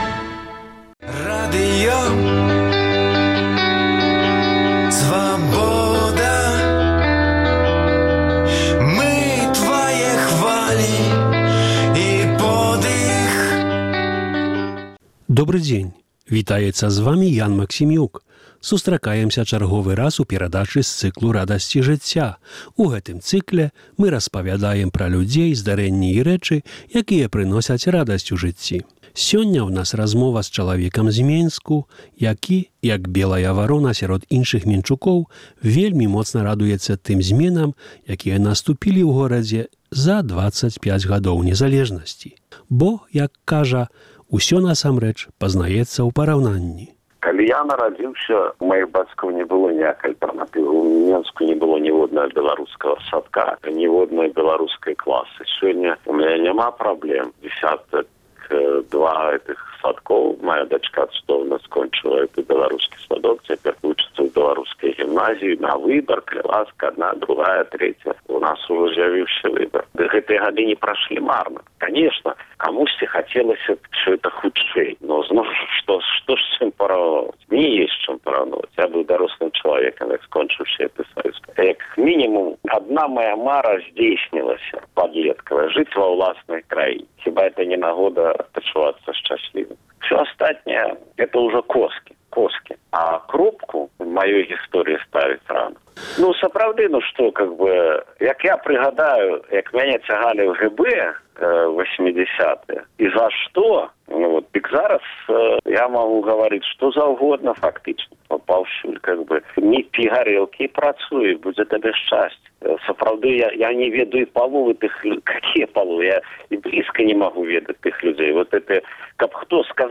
Размова